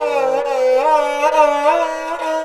SARANGHI3 -R.wav